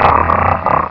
pokeemerald / sound / direct_sound_samples / cries / mightyena.aif